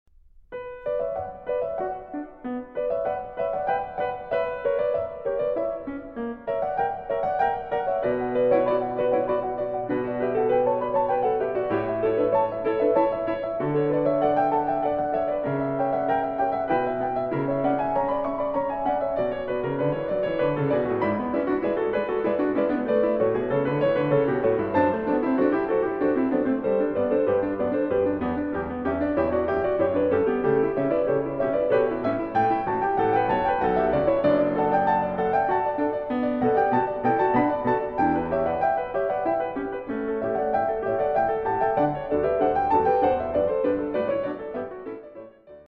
in einer Transkription für zwei Klaviere